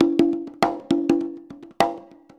100 CONGAS04.wav